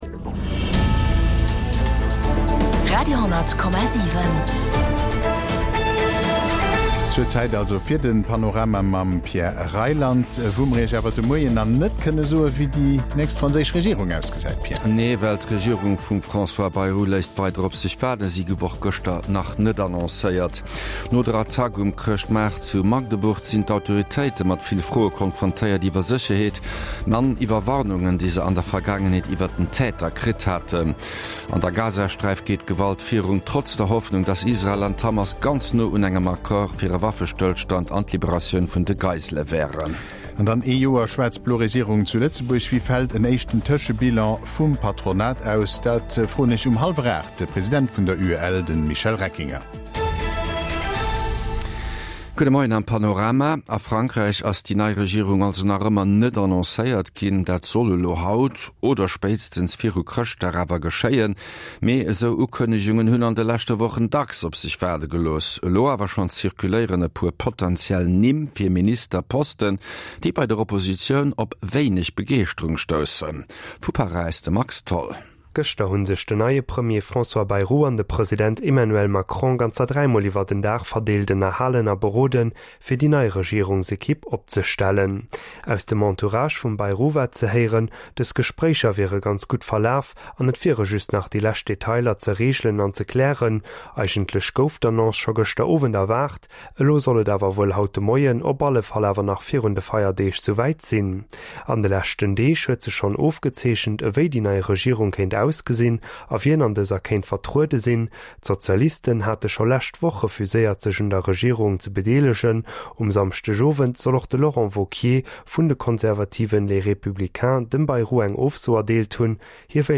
National an international Noriichten